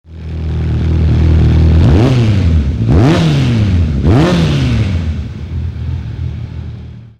Quelques sons de pots au format mp3:
pot_imola.mp3